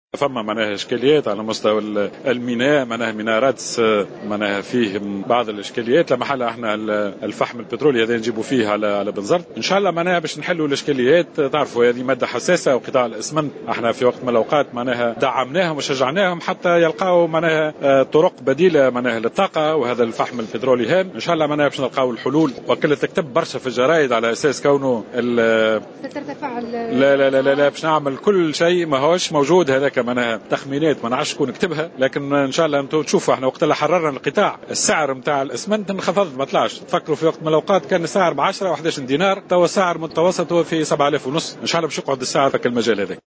كما نفى الوزير أيضا في تصريح لمراسلة "الجوهرة أف أم" الأخبار التي تم ترويجها بخصوص الترفيع في سعر الاسمنت جراء نقص مادة الفحم البترولي.